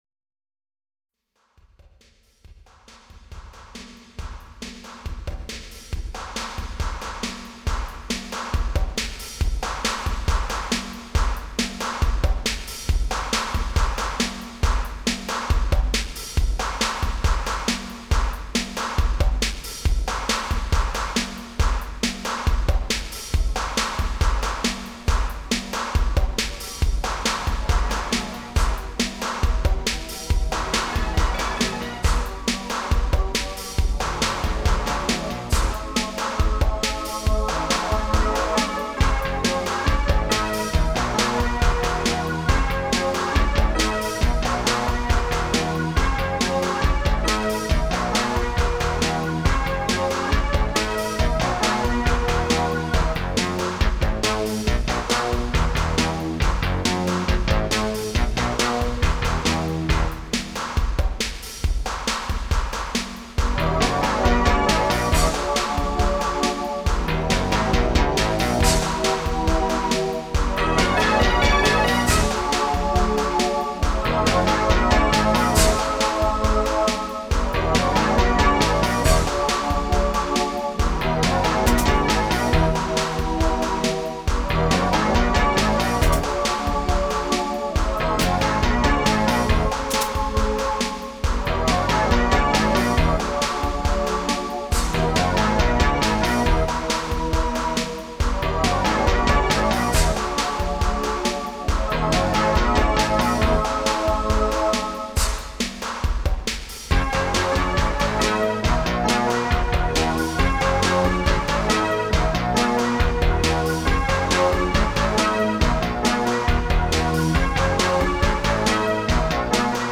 ALL INSTRUMENTAL SOLO PROJECTS